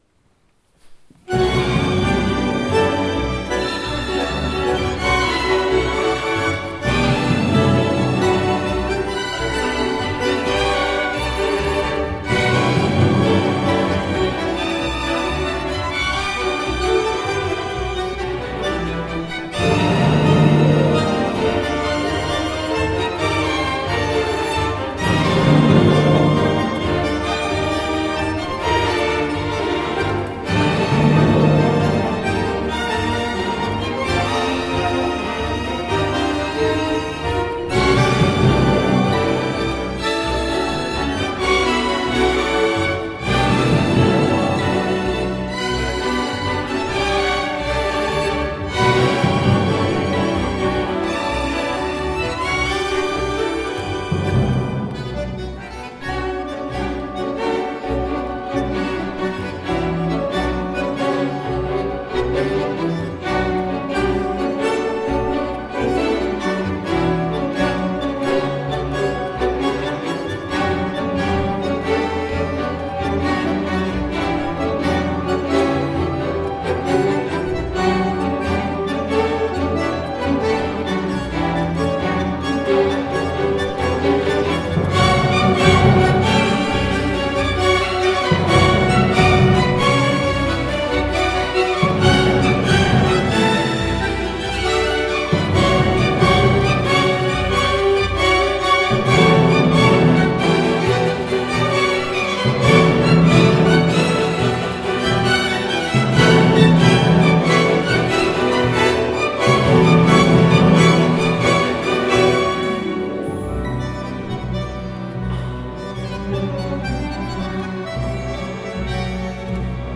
gespielt 12. Juni 2016 in Rheinberg